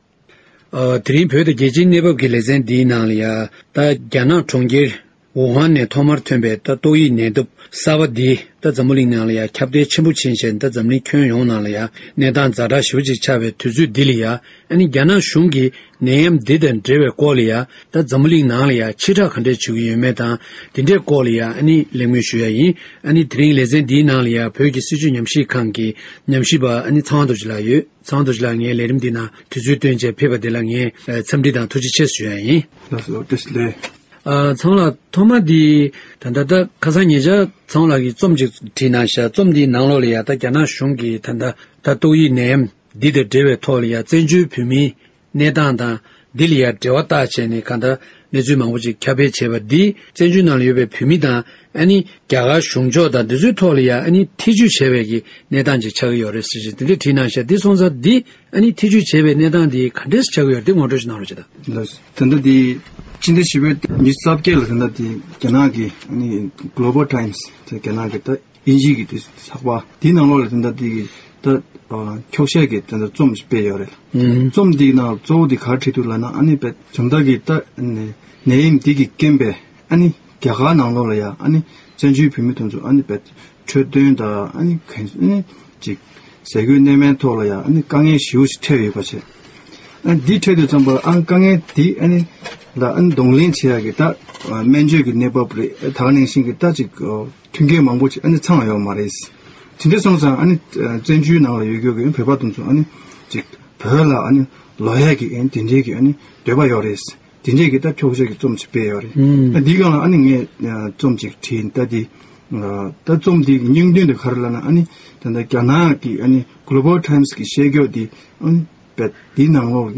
རྒྱ་ནག་གི་ཚགས་པར་ཐོག་རྒྱ་གར་ནང་ཡོད་པའི་བོད་མི་བཙན་བྱོལ་བ་རྣམས་ཕྱིར་བོད་ནང་ལོག་འདོད་པ་བརྗོད་དེ་འཁྱོག་བཤད་ཀྱི་རྩོམ་སྤེལ་ཡོད་པའི་ཐད་གླེང་མོལ།